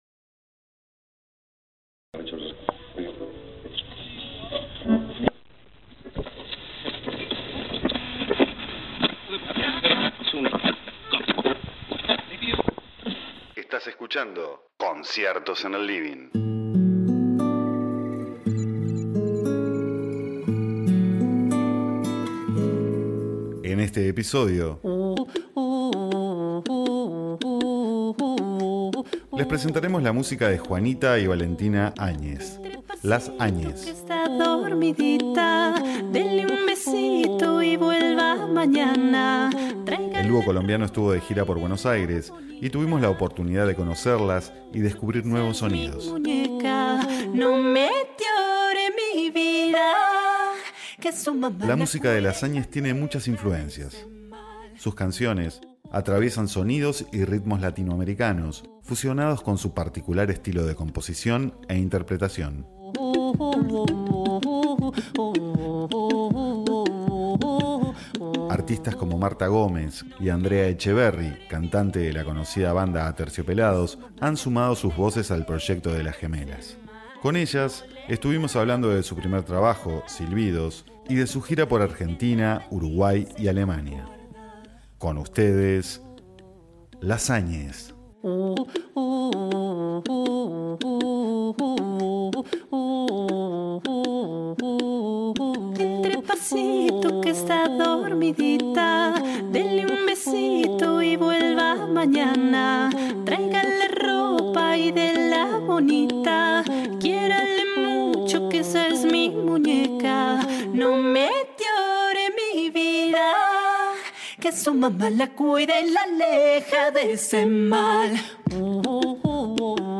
dúo colombiano